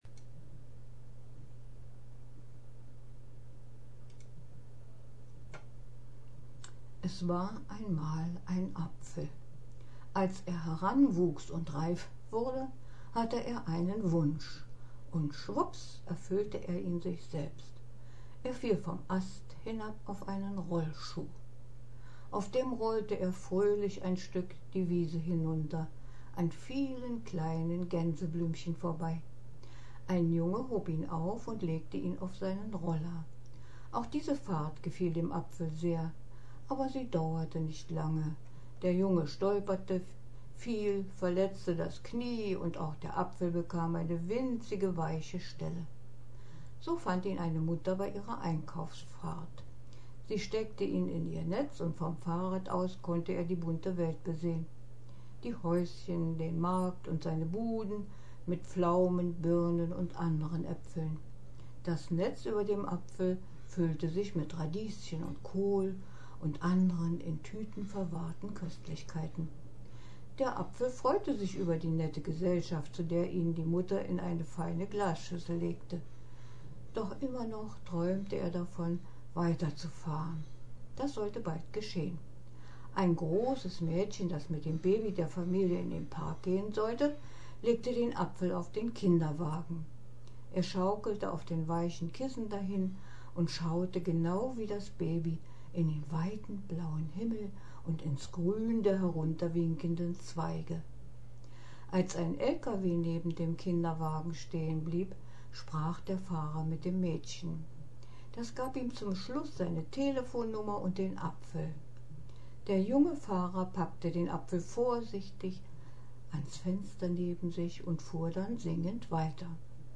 (Gesprochener Text)